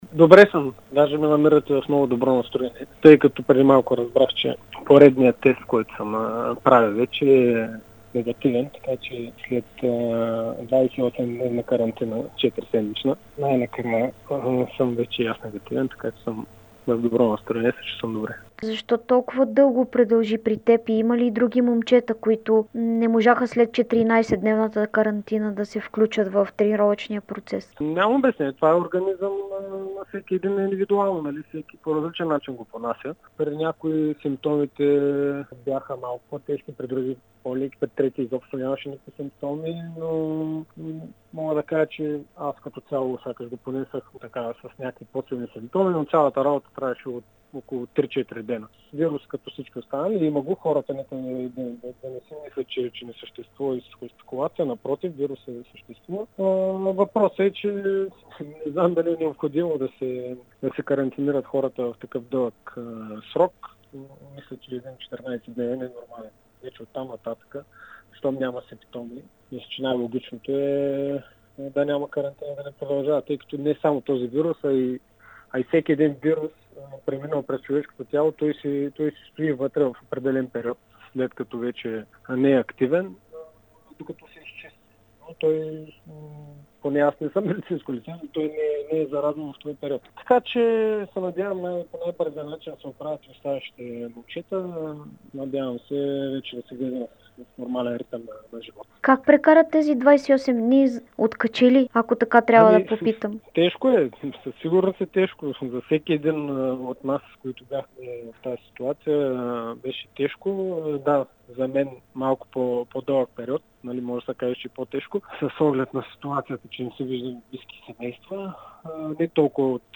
Капитанът на Черно море Даниел Димов даде специално интервю пред dsport и Дарик радио. След 28 дни под карантина той най-накрая даде отрицателен тест за COVID-19 и може да започне подготовка за новия сезон. Димов призна, че поради многото заразени в тима, Черно море ще започне неподготвен новата кампания.